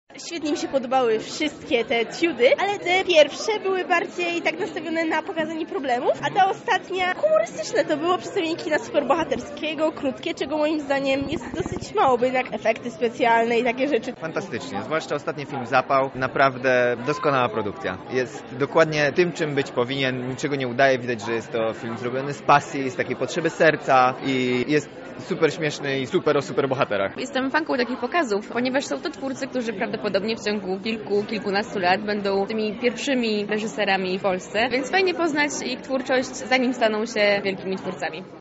Więcej o wrażeniach z festiwalu mówią sami widzowie